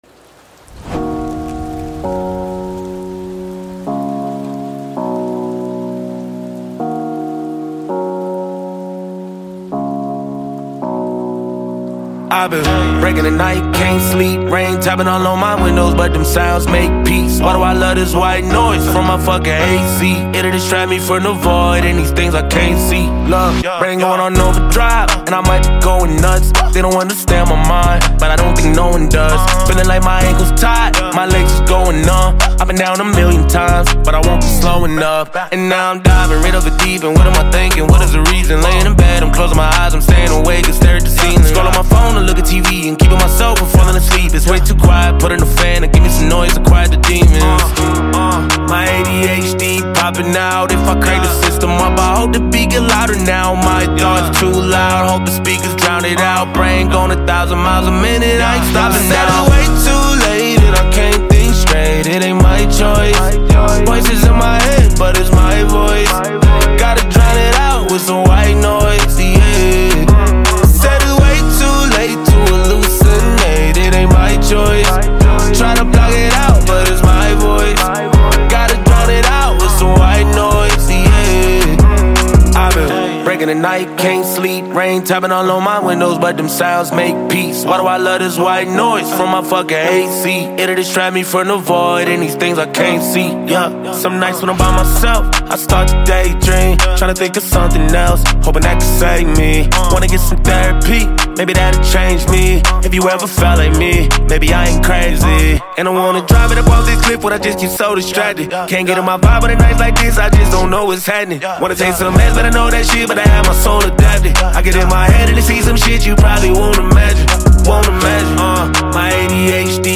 smooth and engaging tune